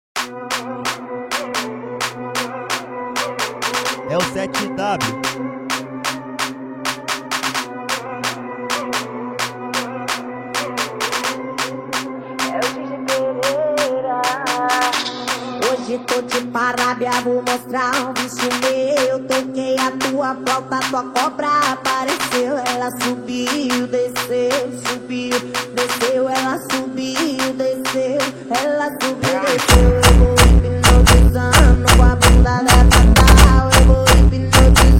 Жанр: Фанк
# Baile Funk